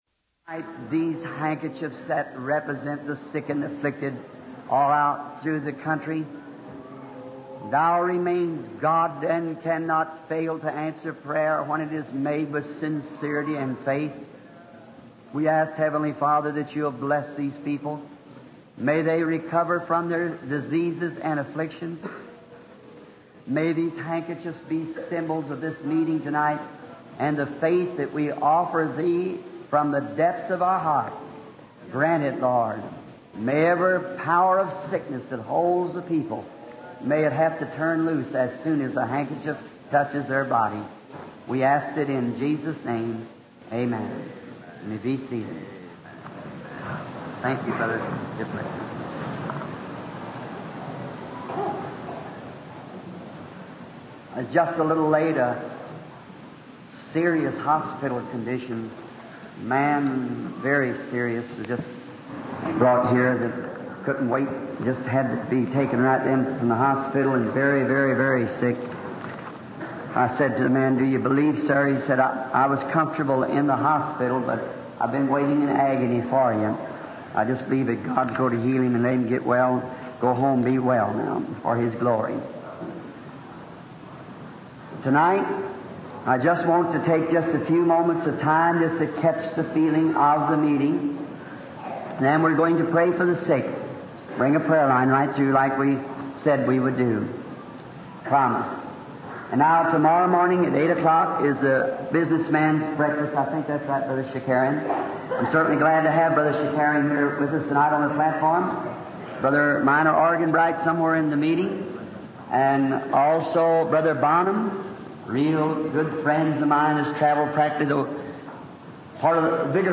Dieses Portal gibt Ihnen die Möglichkeit, die ca. 1200 aufgezeichneten Predigten von William Marrion Branham aufzurufen, zu lesen oder zu hören.